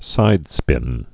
(sīdspĭn)